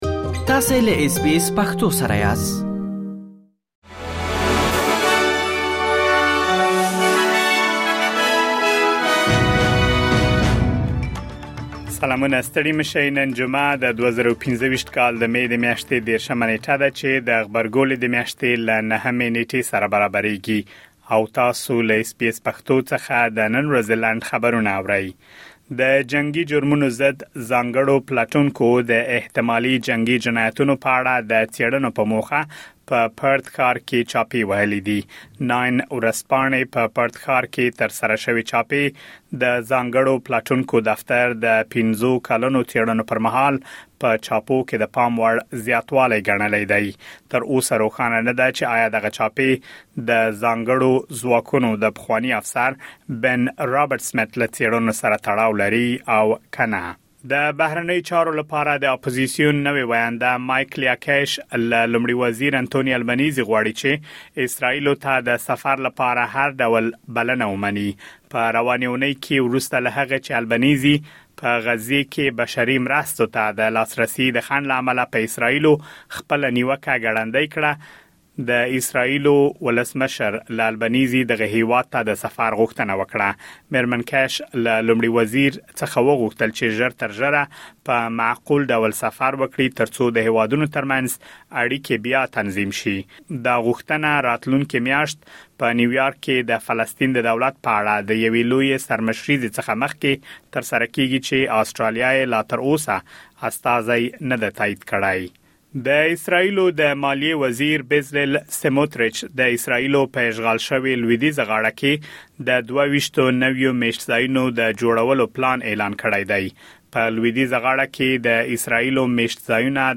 د اس بي اس پښتو د نن ورځې لنډ خبرونه | ۳۰ مې ۲۰۲۵